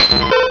Cri de Métamorph dans Pokémon Rubis et Saphir.